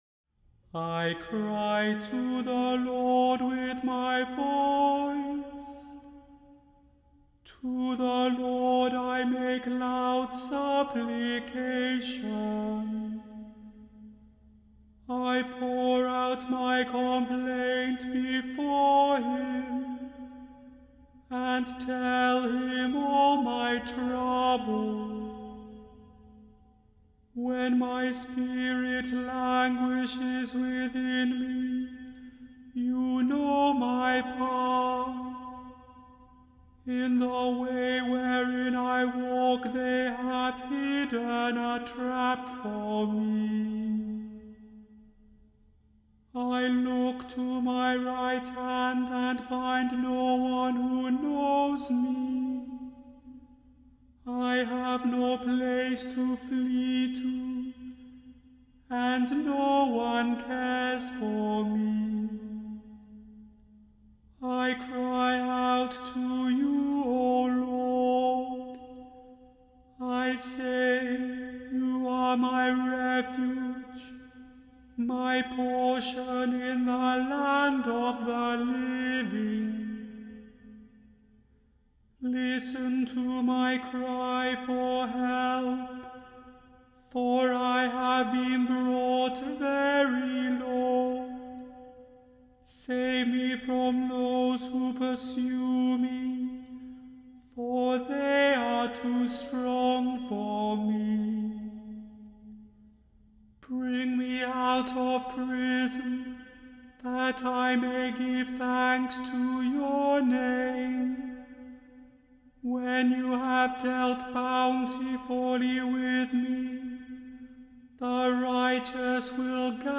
The Chant Project – Chant for Today (September 2) – Psalm 142